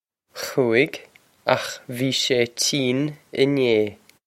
Pronunciation for how to say
Khoo-ig, okh vee shay cheen in-ay.
This is an approximate phonetic pronunciation of the phrase.